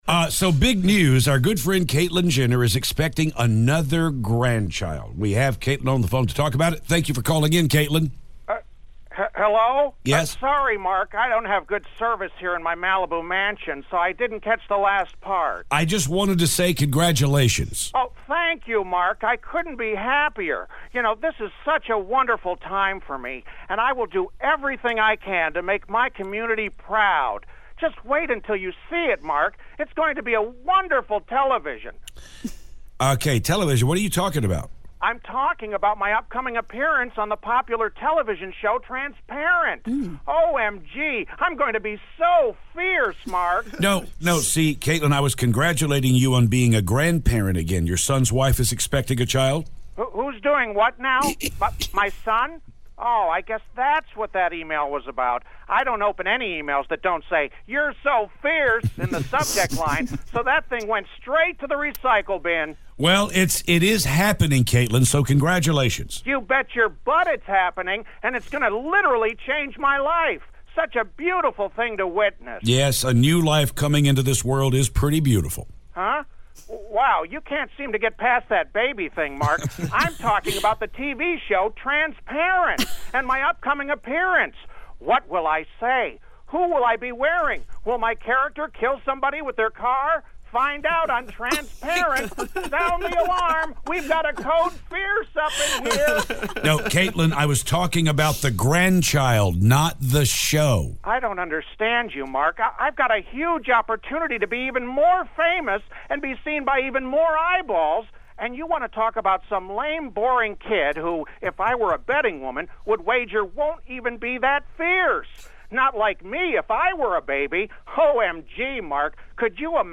Caitlyn Jenner Phoner
Caitlyn Jenner calls to talk about her new grandchild.